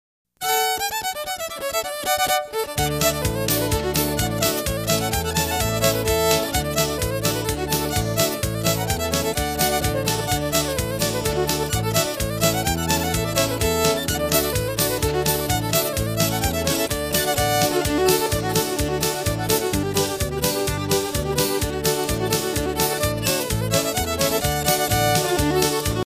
Fiddle Music